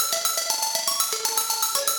SaS_Arp01_120-A.wav